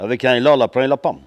Maraîchin
Patois
Catégorie Locution